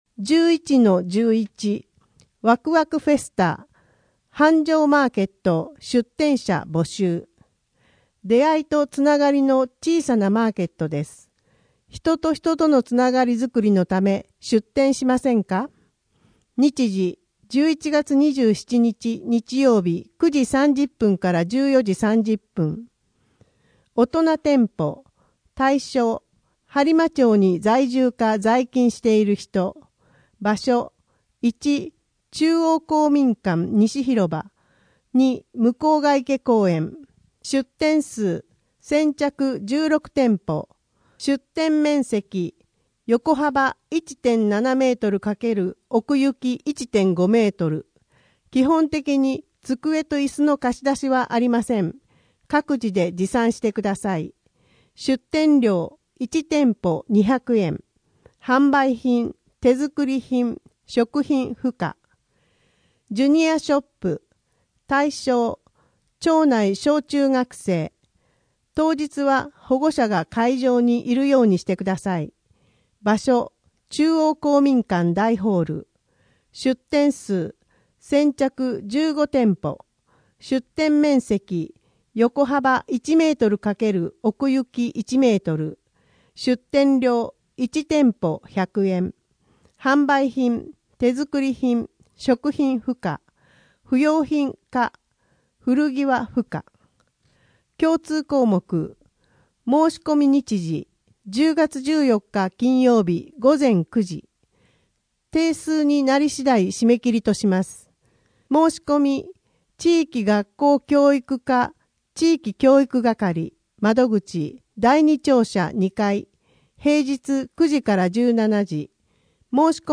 声の「広報はりま」10月号
声の「広報はりま」はボランティアグループ「のぎく」のご協力により作成されています。